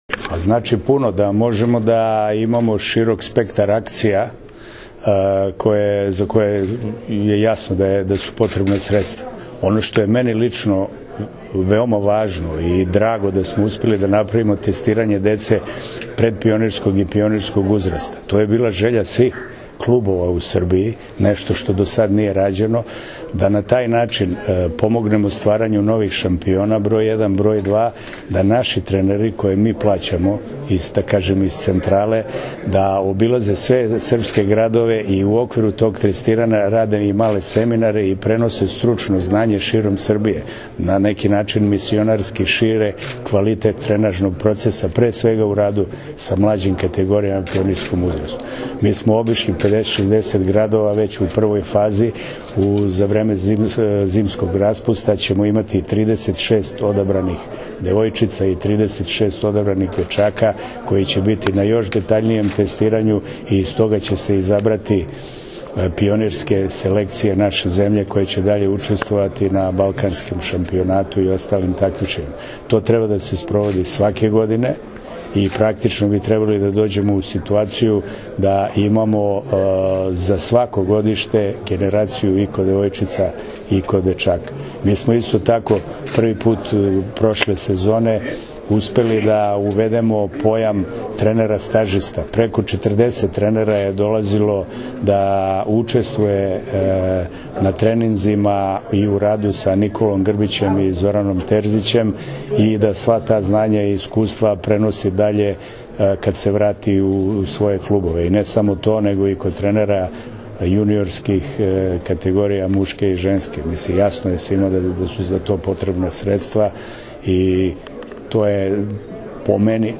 Odbojkaški savez Srbije i Banka Poštanska štedionica potpisali su danas Ugovor o generalnom sponzorstvu u beogradskom hotelu „Kraun Plaza“.
Izjava Zorana Gajića